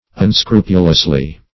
-- Un*scru"pu*lous*ly, adv.